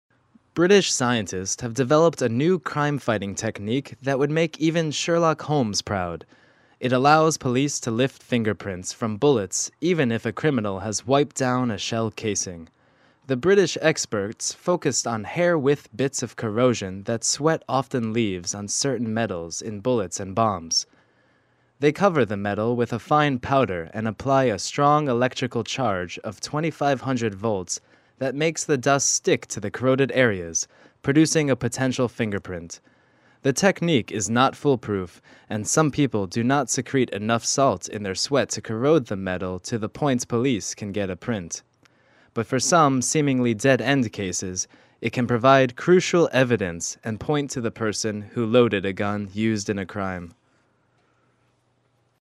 Начитка диктора на английском языке №1 Категория: Аудио/видео монтаж